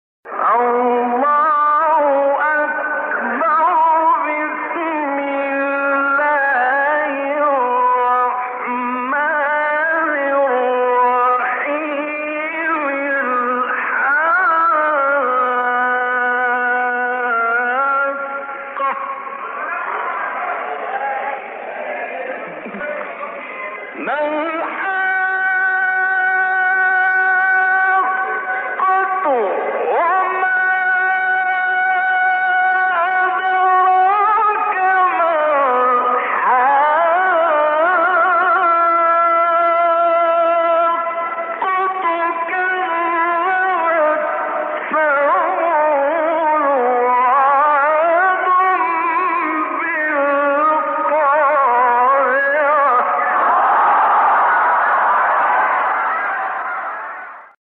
فرازهای صوتی از قاریان به‌نام مصری